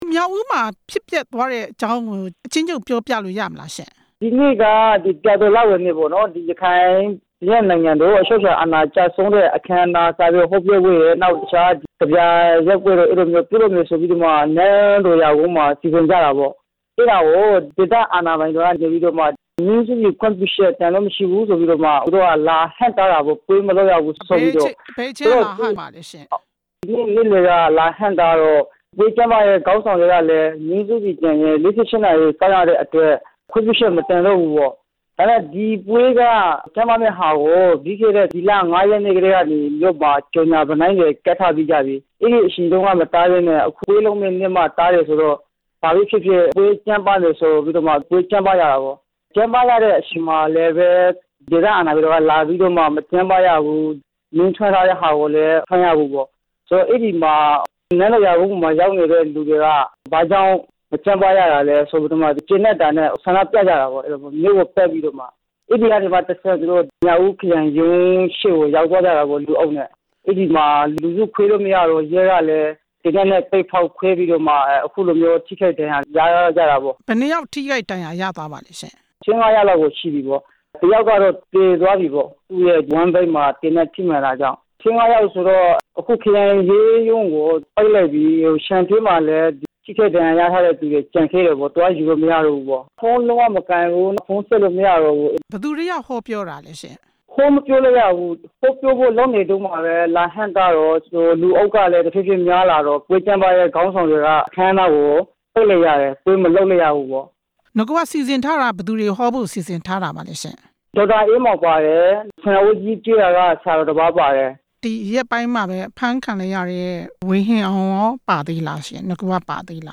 မြောက်ဦးမြို့ ပဋိပက္ခ အခြေအနေ ဆက်သွယ်မေးမြန်းချက်